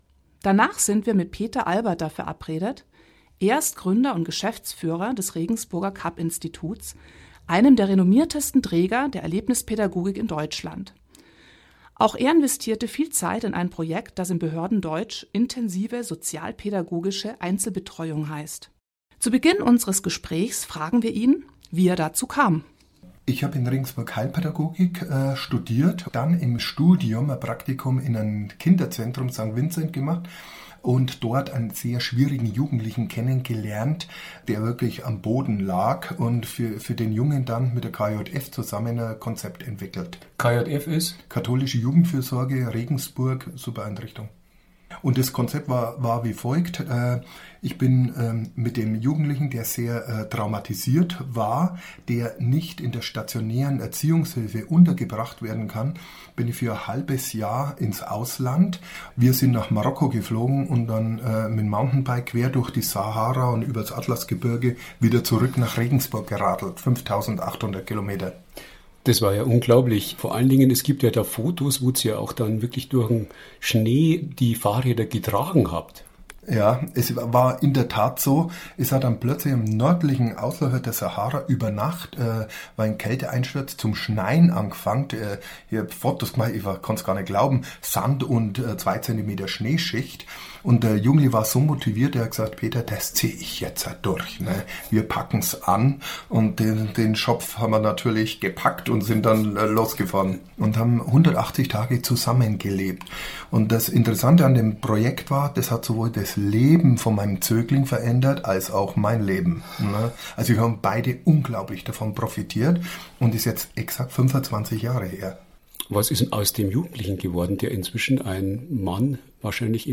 12.06.19 – Radiointerview LORA
Experteninterview zum Thema „Bewegtes Lernen“